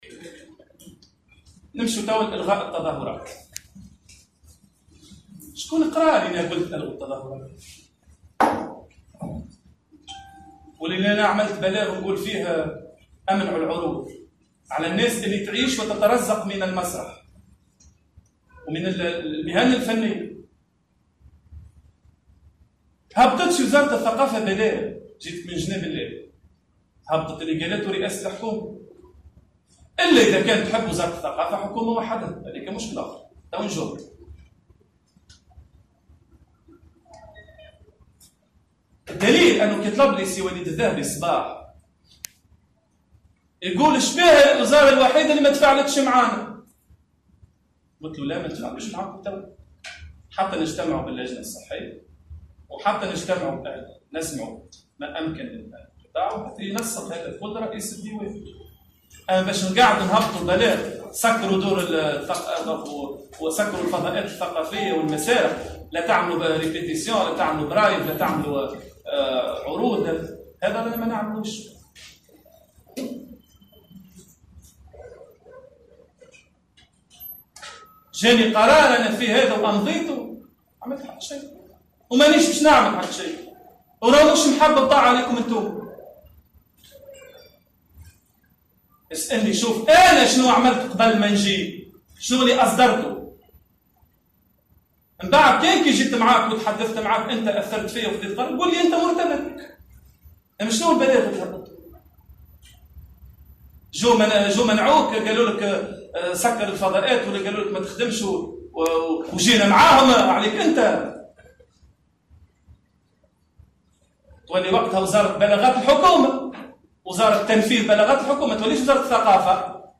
في خطاب شديد اللهجة: وزير الثّقافة يرفض الامتثال لقرار المشّيشي بخصوص تعليق الأنشطة الثقافية (فيديو)